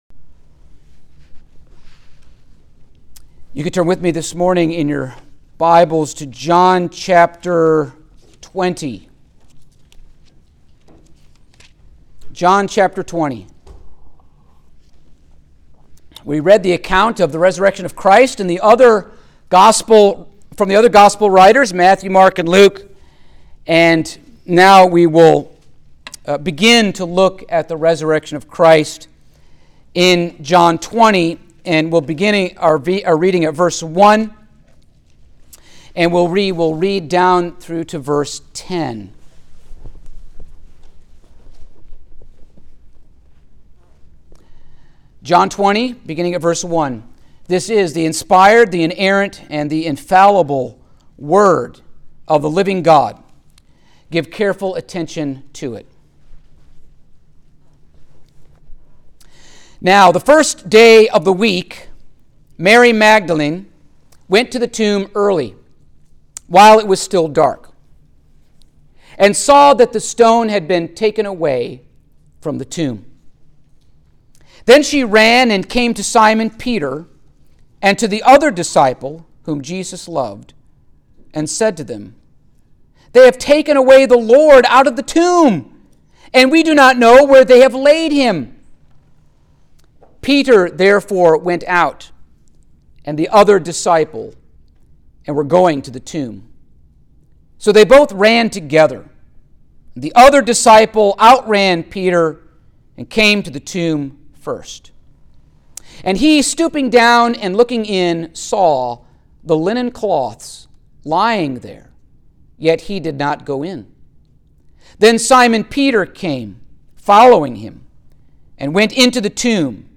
Passage: John 20:1-10 Service Type: Sunday Morning